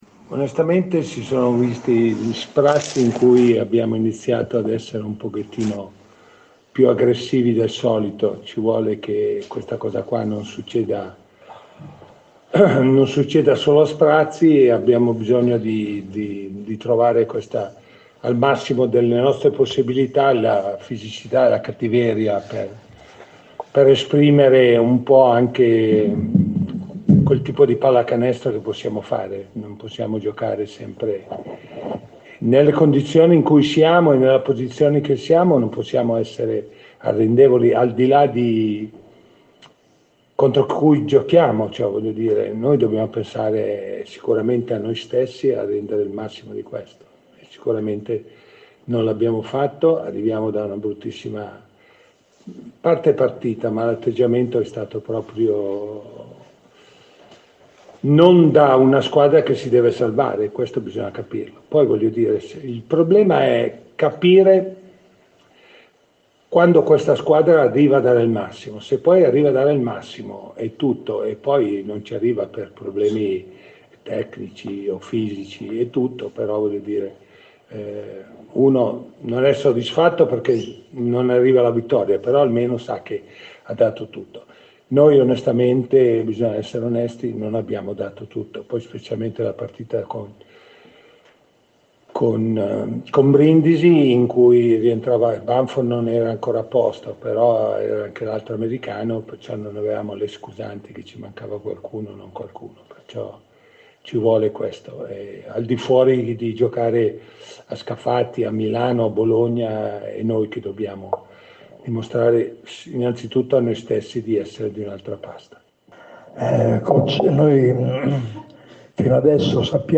Eccolo ai nostri microfoni.